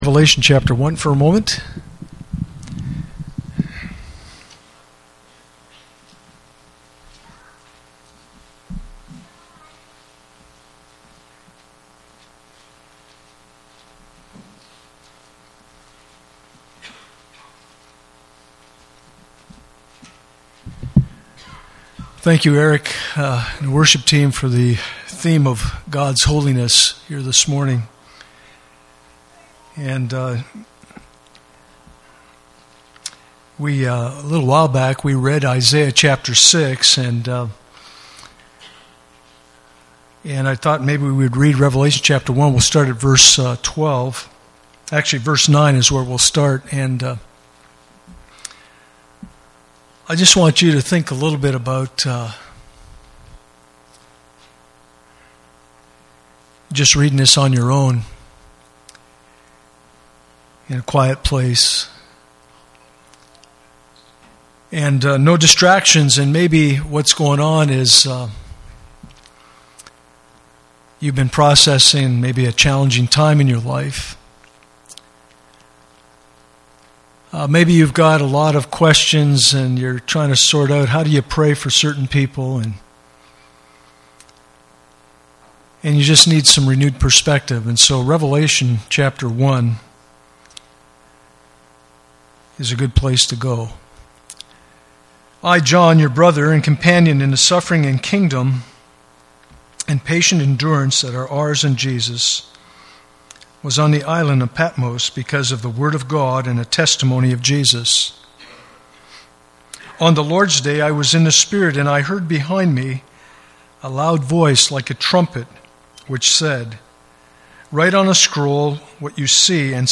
Isaiah 55:1-1:6 Service Type: Sunday Morning « Advent Through the Eyes of Isaiah